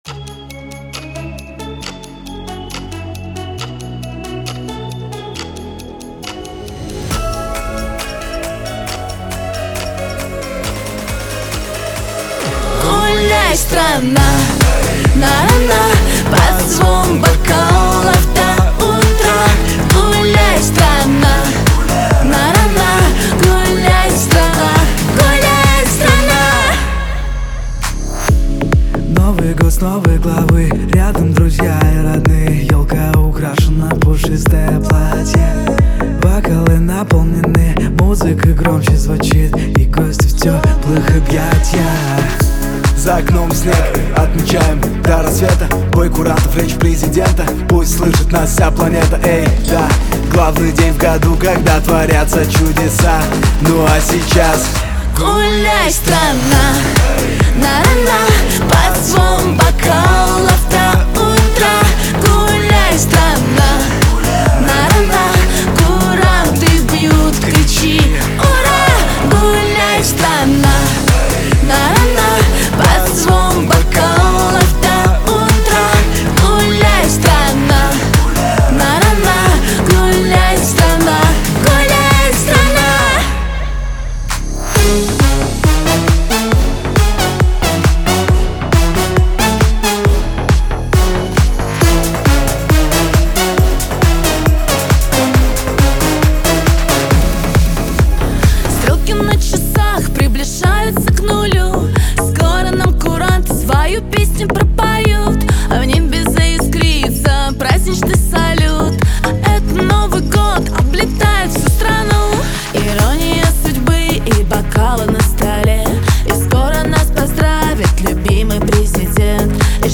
новый новогодний трек